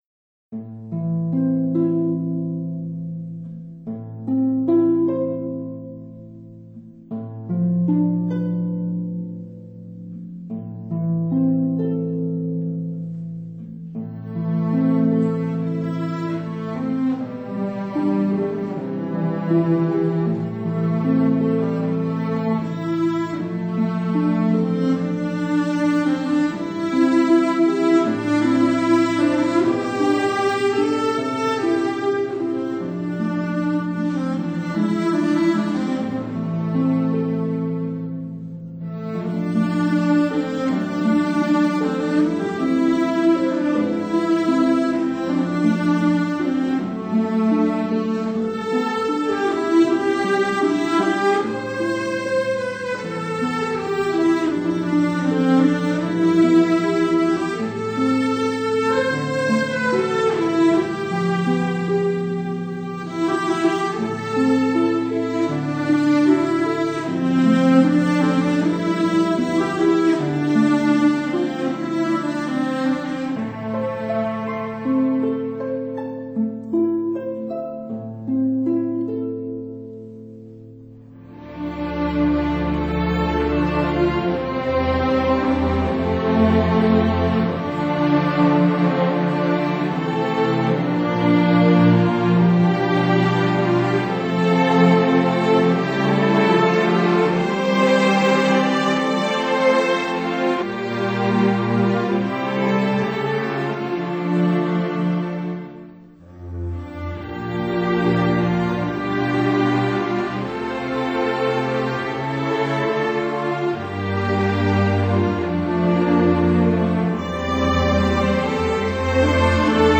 最佳流行演奏唱片大奖